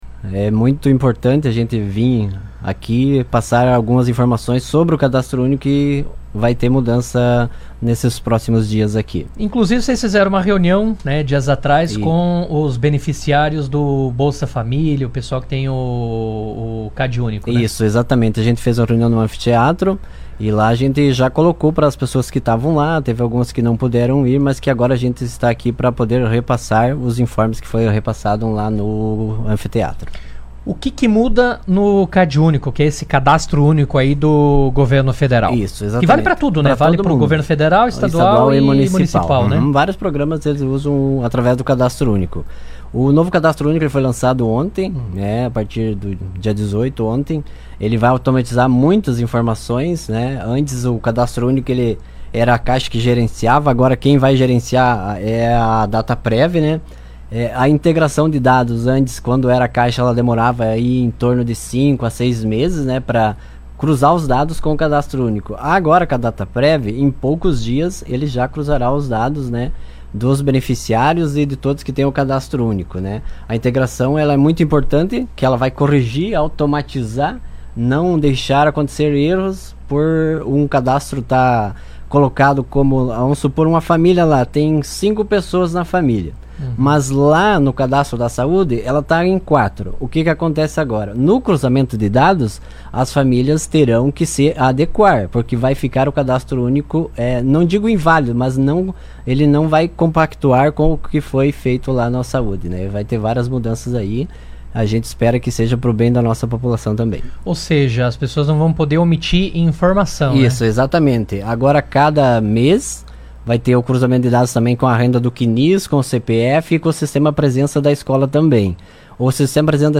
Durante a entrevista, ele explicou as novas diretrizes e destacou a importância da atualização cadastral para a manutenção dos benefícios sociais.